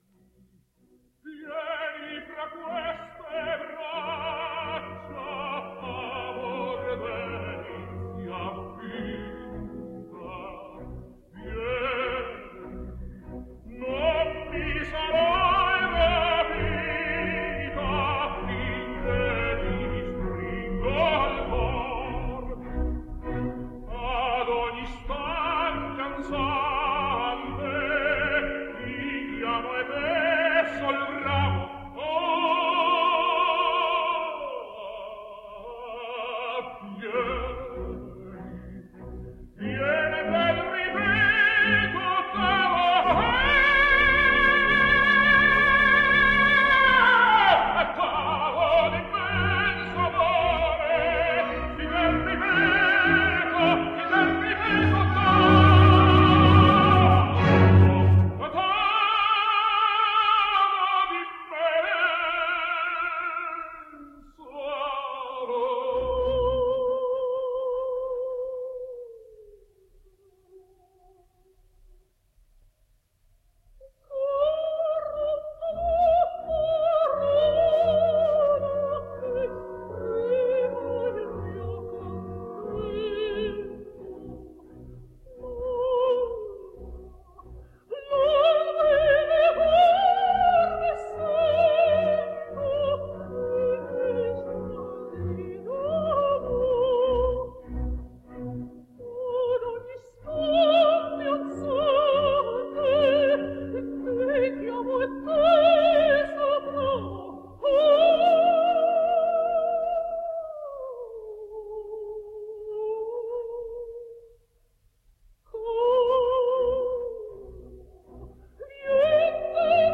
Italian tenor.